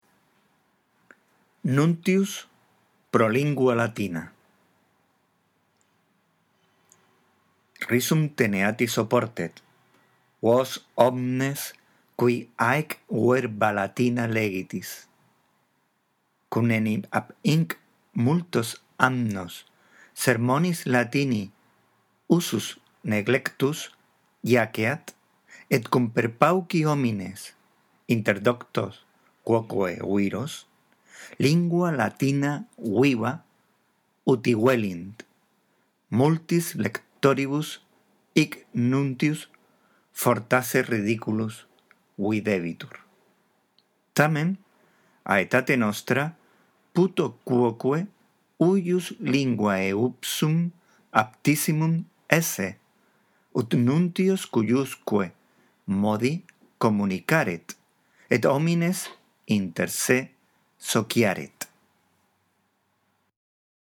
La audición de este archivo te ayudará en la práctica de la lectura del latín